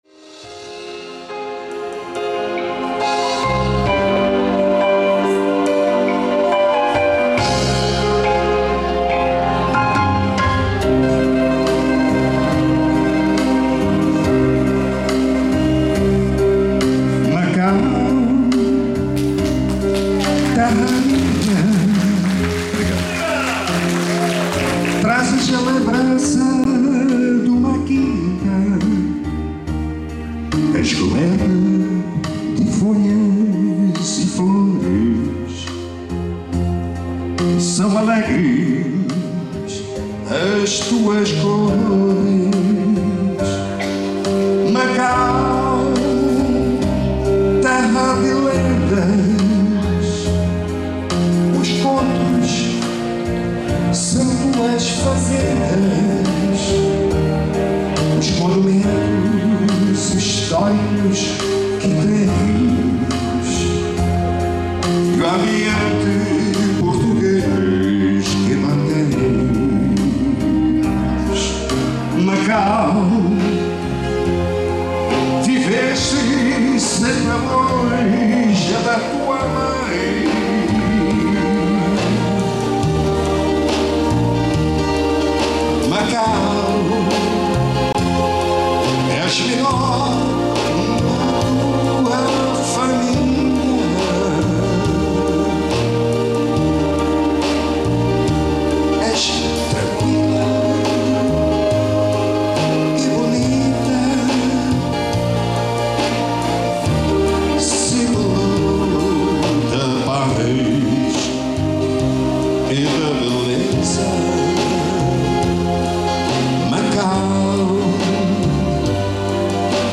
Produziu e executou todo o acompanhamento musical, e gravou para esta apresentação memorável, especialmente por ter acontecido no próprio DIA DE MACAU, cuja festa foi realizada pela Casa de Macau de São Paulo em 24 de Junho de 2012. No fim da apresentação, o público aplaudiu efusivamente e sguiram-se muitos cumprimentos e fotos com o artista.
É uma versão suave e ao mesmo tempo sofisticada.